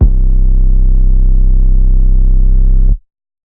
808 Murda.wav